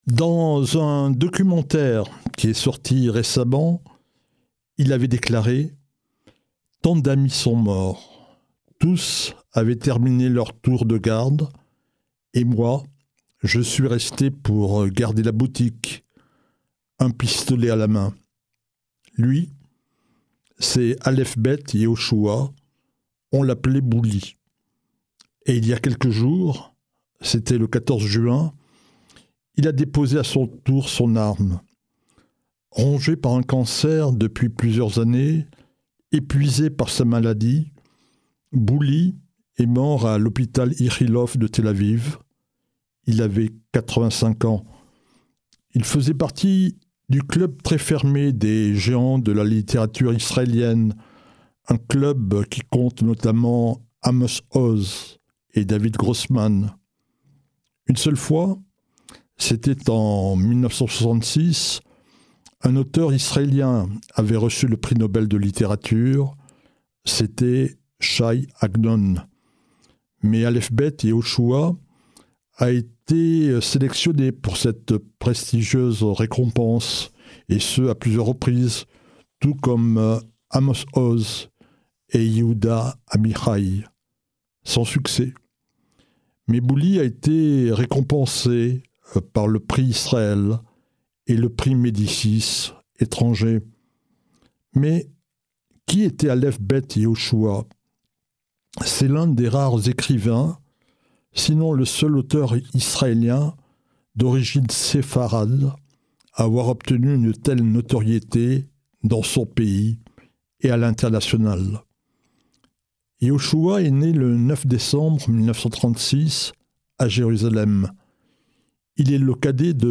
chronique « Un jour, une histoire » diffusée sur Radio Shalom